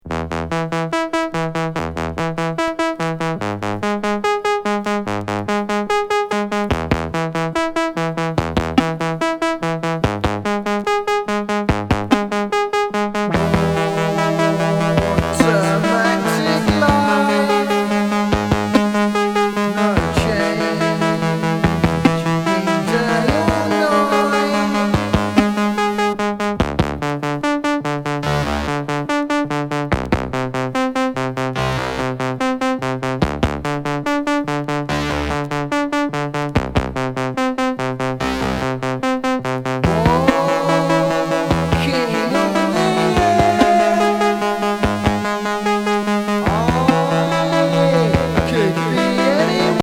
カルトUS宅録シンセ・ミニマルNW！！
+パンクやるせないVOの珍作！